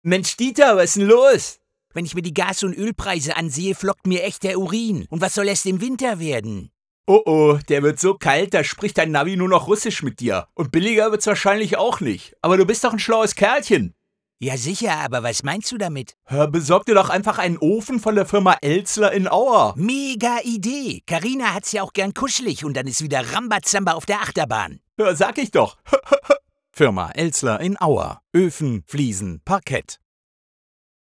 Radiospot 2022